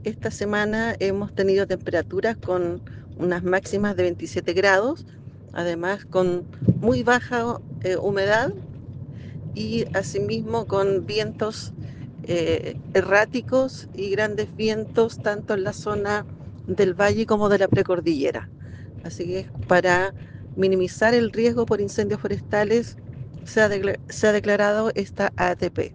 La directora de Conaf en La Araucanía, María Teresa Huentequeo, explicó parte de esta situación y dijo que la idea es minimizar el riesgo.